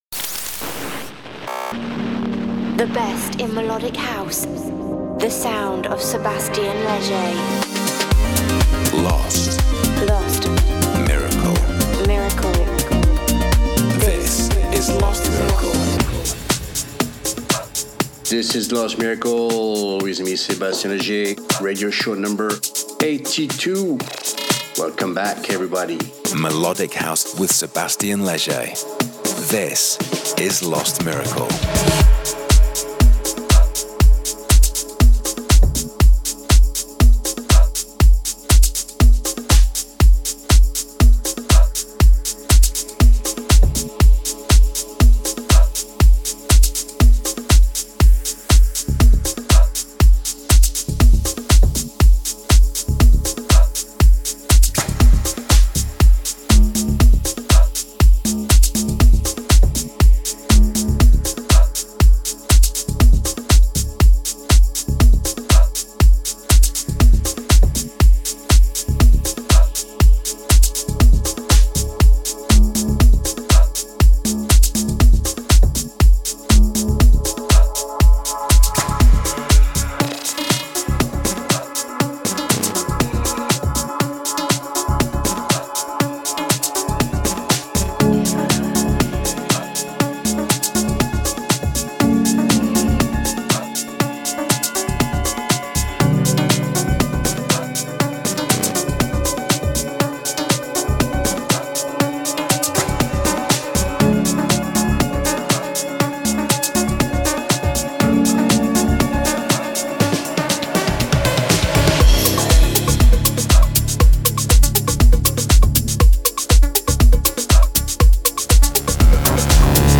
the monthly radio show